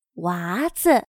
娃子/Wázi/[En el habla coloquial] bebé; niño.